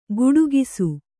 ♪ guḍugisu